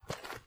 HauntedBloodlines/STEPS Dirt, Walk 19.wav at 545eca8660d2c2e22b6407fd85aed6f5aa47d605
STEPS Dirt, Walk 19.wav